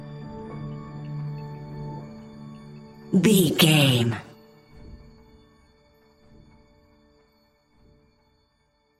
Ionian/Major
D♭
laid back
Lounge
sparse
new age
chilled electronica
ambient
atmospheric